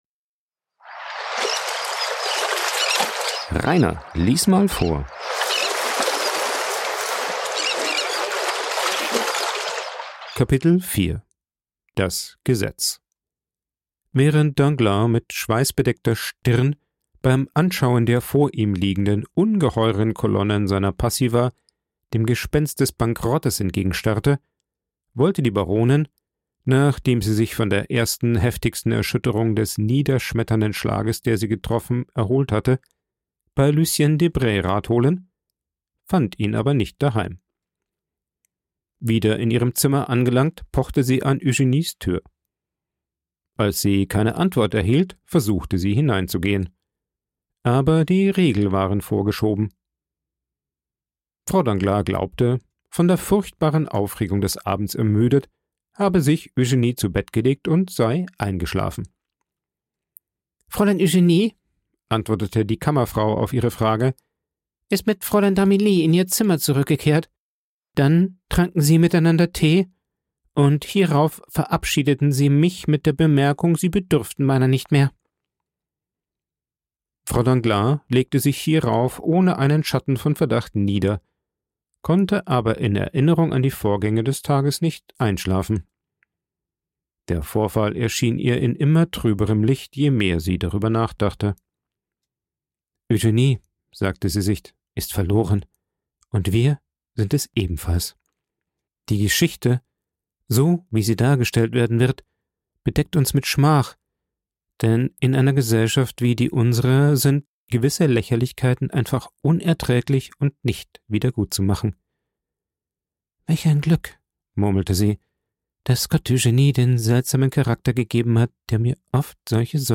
Villefort allerdings, durch 3 Todesfälle getroffen findet nur noch Trost in seiner Arbeit für das das Gesetz. Vorgelesen
aufgenommen und bearbeitet im Coworking Space Rayaworx, Santanyí, Mallorca.